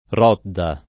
Rodda [ r 0 dda ] top. (Friuli)